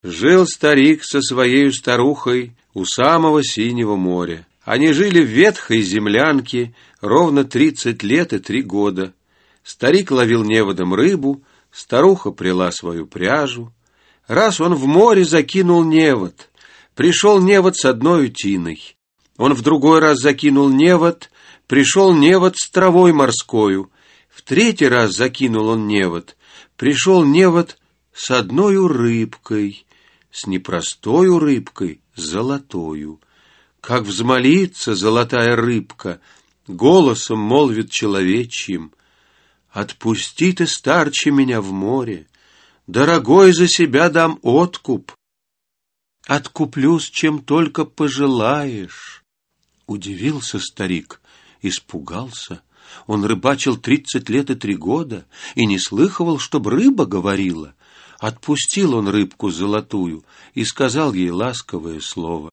Аудиокнига Сказки | Библиотека аудиокниг